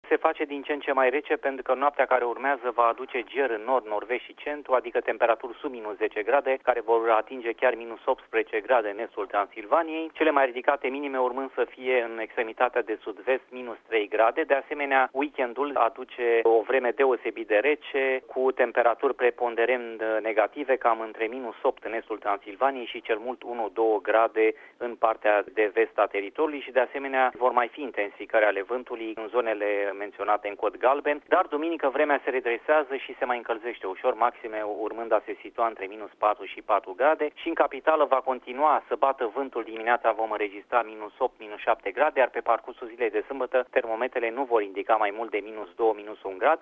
Meteorologul